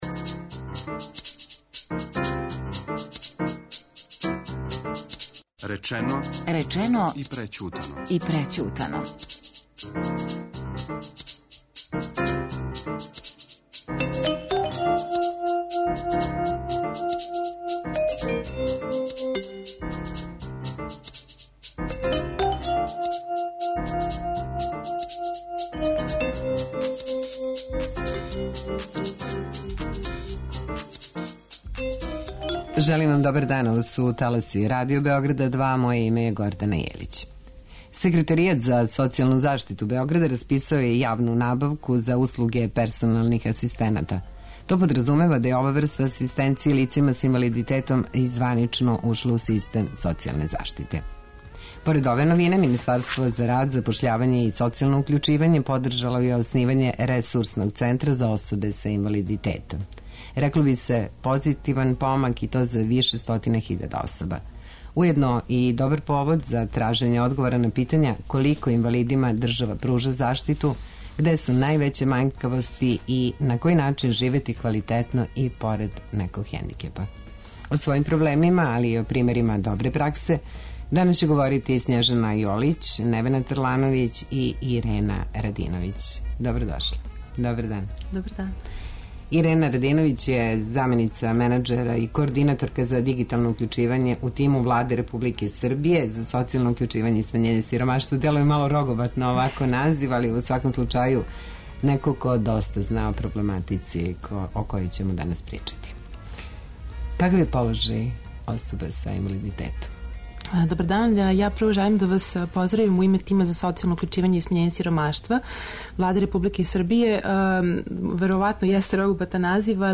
Реприза емисије о животу са инвалидитетом и најављеним ангажманом персоналних асистената.